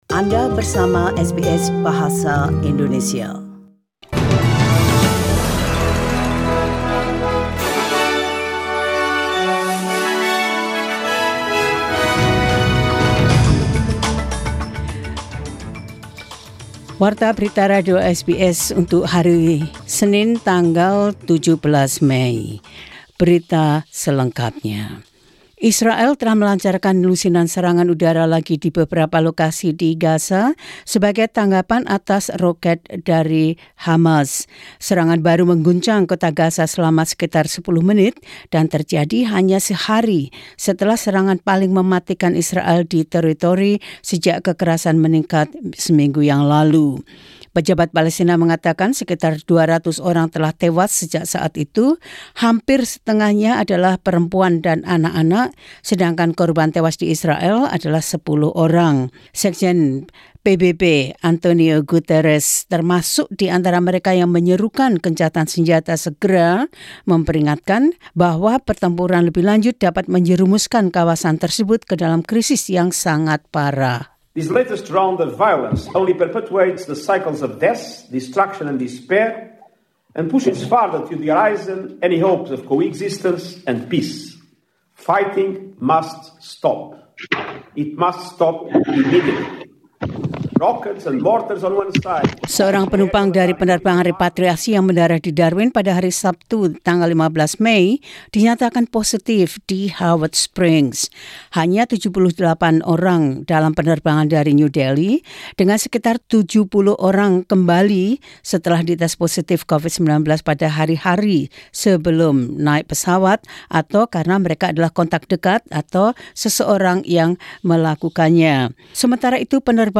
Warta Berita Radio SBS Program Bahasa Indonesia – 17 Feb 2021.